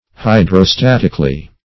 Search Result for " hydrostatically" : The Collaborative International Dictionary of English v.0.48: Hydrostatically \Hy`dro*stat"ic*al*ly\, adv. According to hydrostatics, or to hydrostatic principles.
hydrostatically.mp3